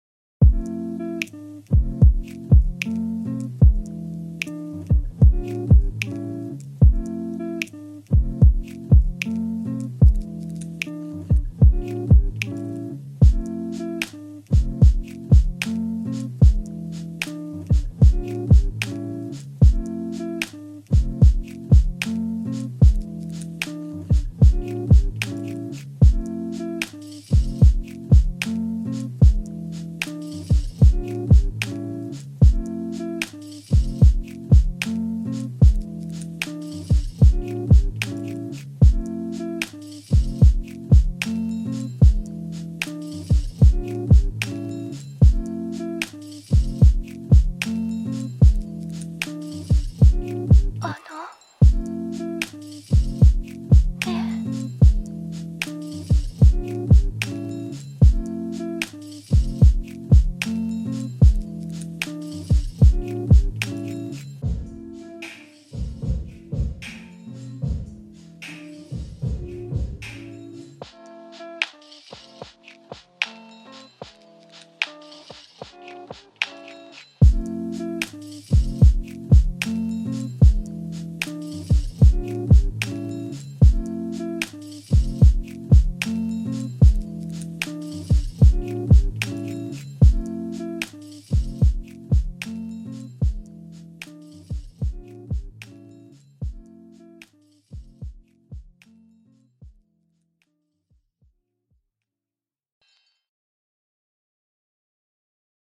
Умиротворенная японская музыка в стиле lo-fi